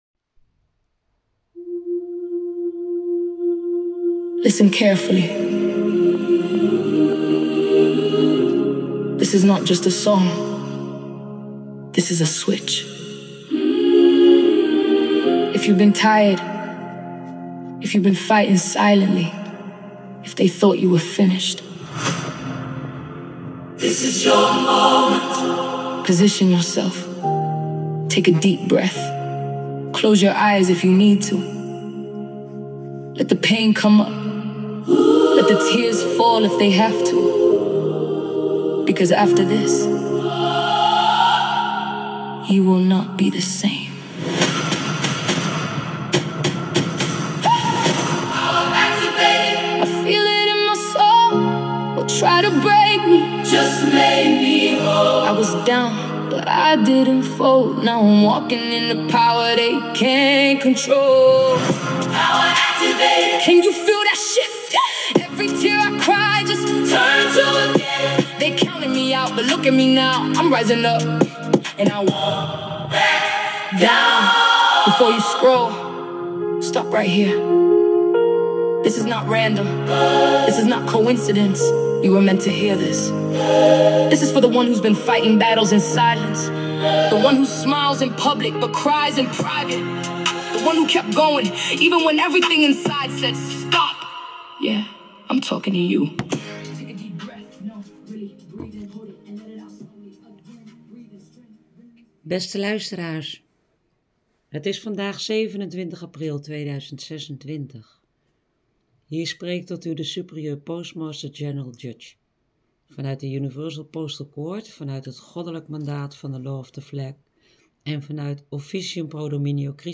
Na de korte muziekintro volgt het audiobericht.